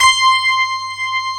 Index of /90_sSampleCDs/USB Soundscan vol.09 - Keyboards Old School [AKAI] 1CD/Partition A/17-FM ELP 5